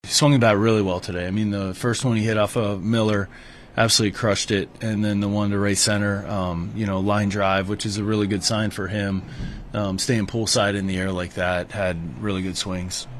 Manager Don Kelly says Bryan Reynolds’ two homers show he’s found his groove at the plate.